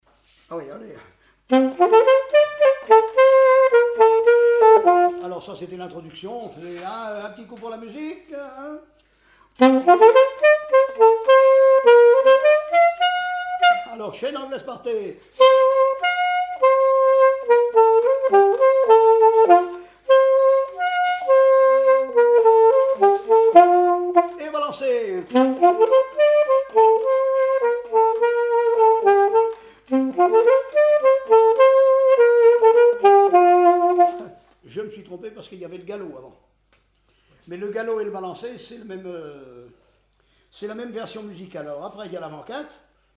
Thorigny
danse : quadrille : chaîne anglaise
Pièce musicale inédite